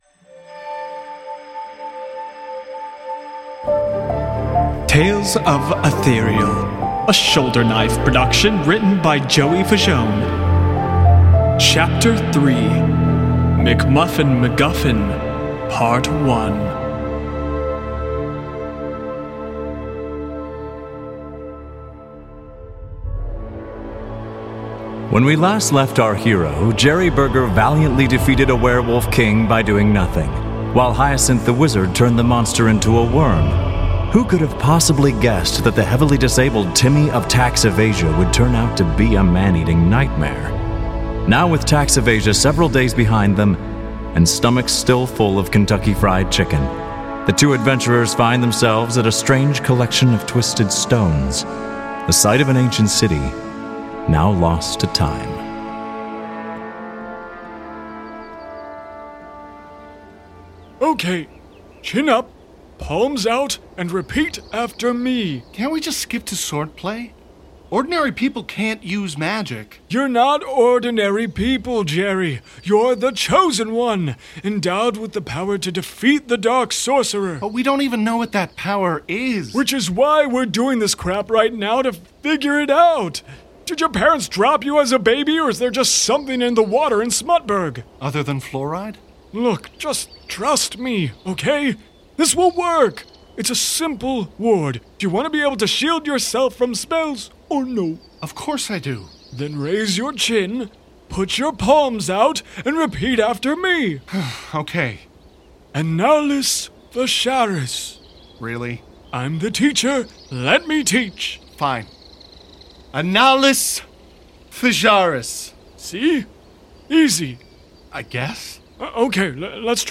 Additional SFX licensed from Pixabay